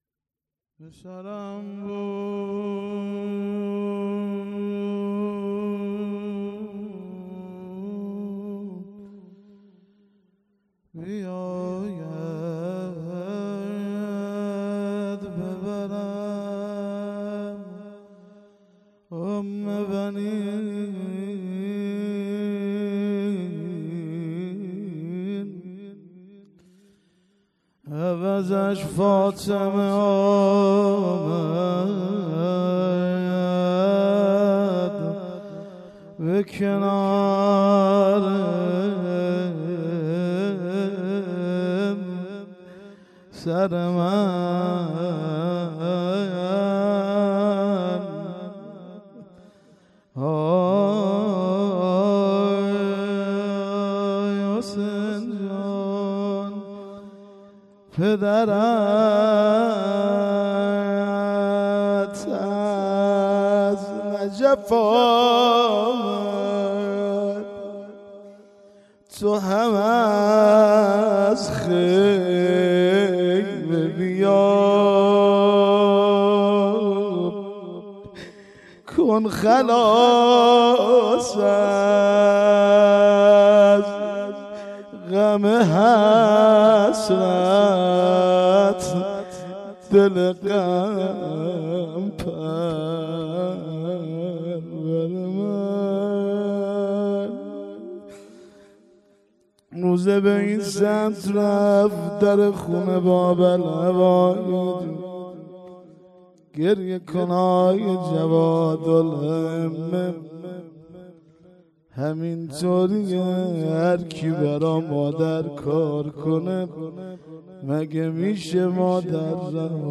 مراسم شهادت امام جواد علیه السلام ۱۴۰۴